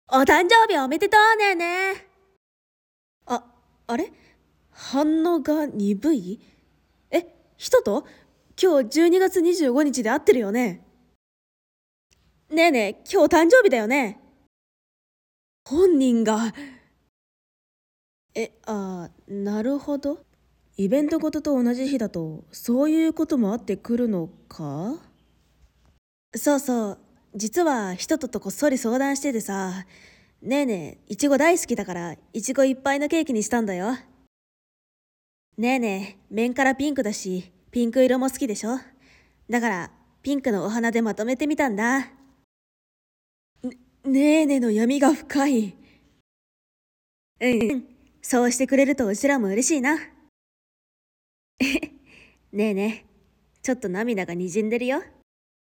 🩷バースデー声劇 💛